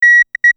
BiteSignal1.ogg